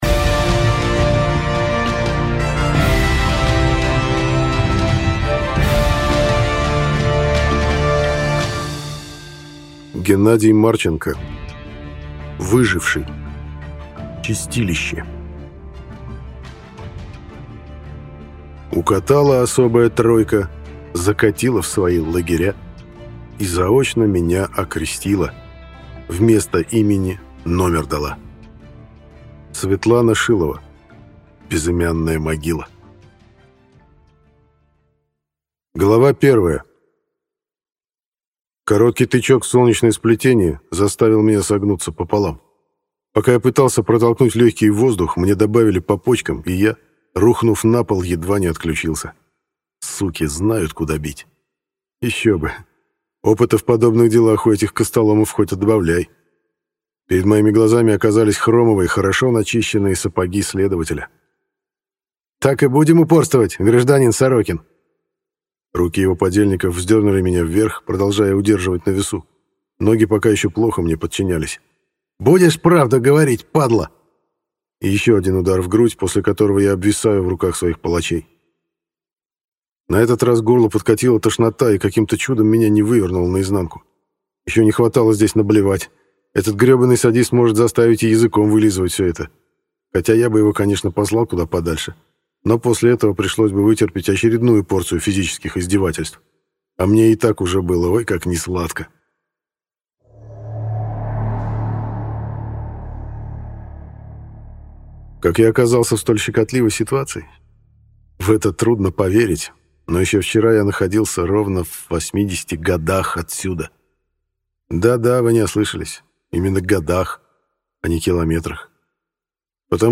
Аудиокнига Выживший. Чистилище | Библиотека аудиокниг